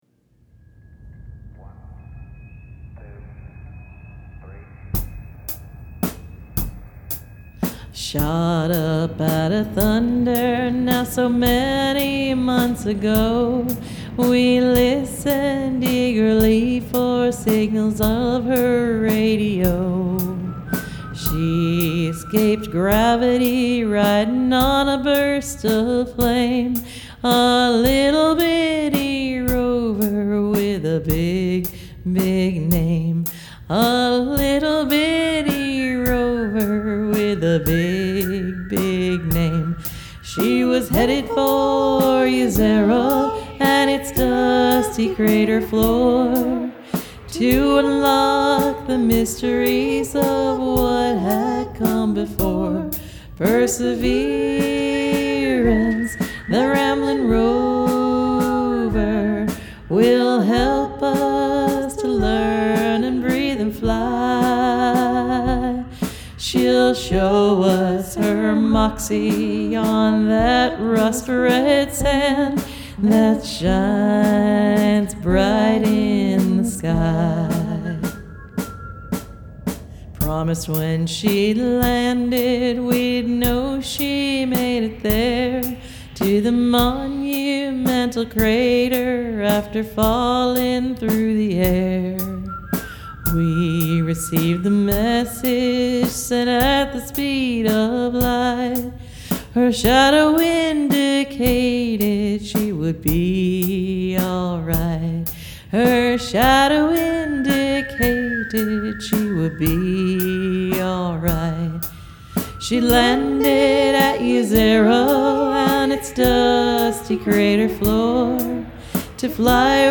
(county waltz space) Dare Mighty Things!
Percussion samples
Sound samples: NASA
* (Yez-zeh-roh)